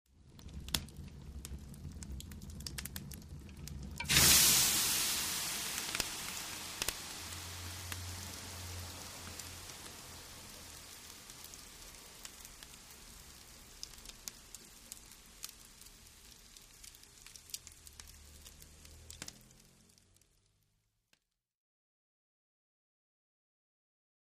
Water, Sizzle | Sneak On The Lot
Water Being Thrown On Fire With Sizzle